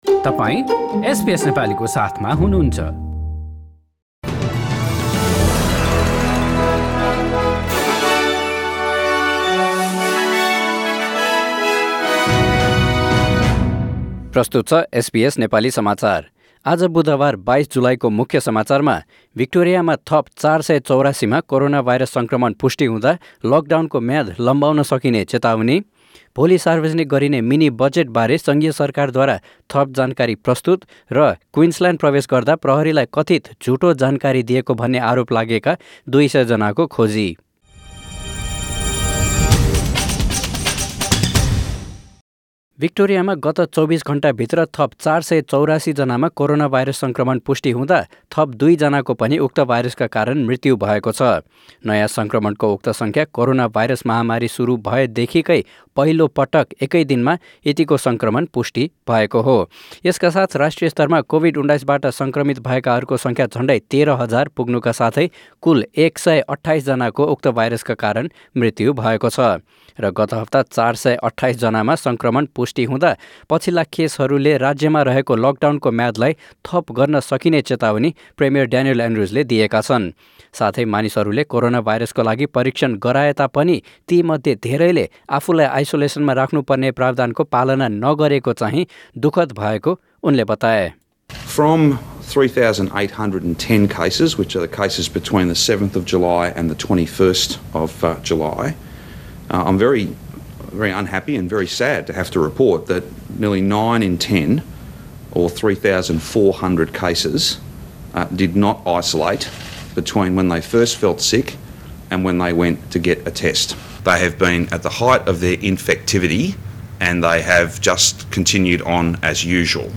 एसबीएस नेपाली अस्ट्रेलिया समाचार: बुधवार २२ जुलाई २०२०